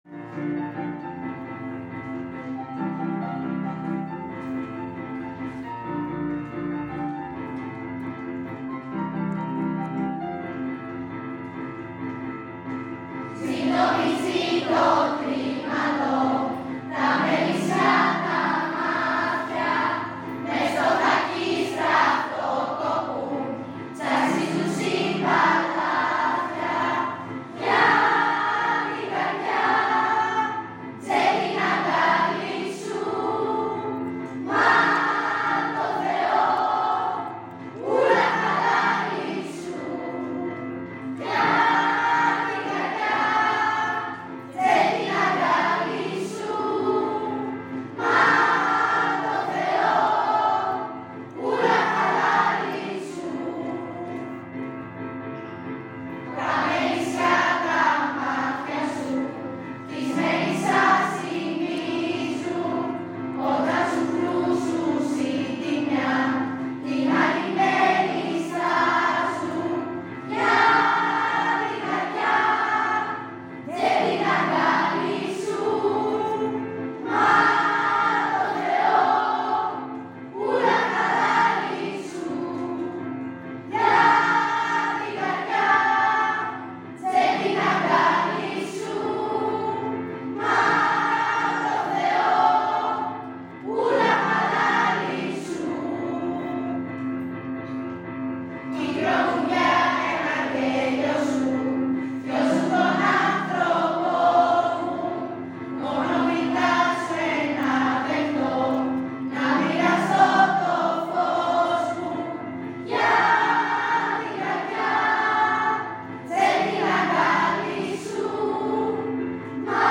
Το τραγούδι που ακολουθεί είναι το “Ούλα χαλάλι σου”, πασίγνωστο Κυπριακό παραδοσιακό τραγούδι που αποδόθηκε από τη χορωδία μας, στα πλαίσια της γνωριμίας των μαθητών με την μουσική παράδοση της Κύπρου.